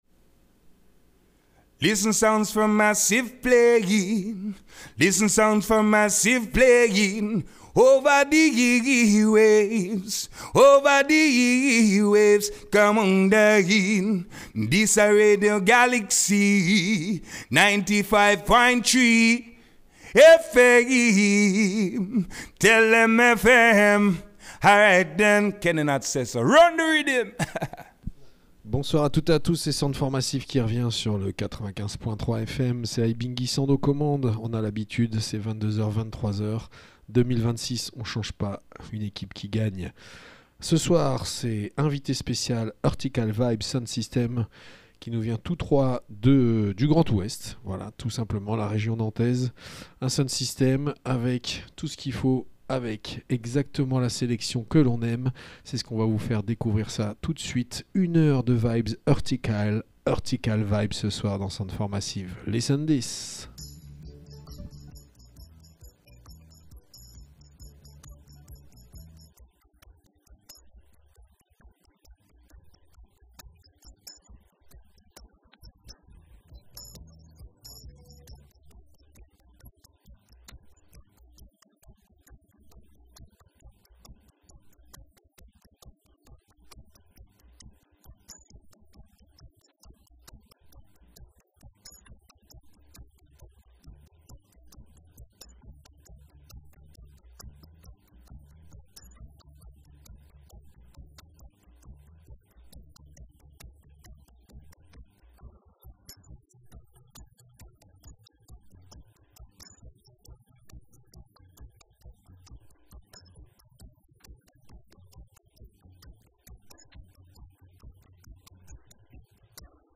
reggaephonique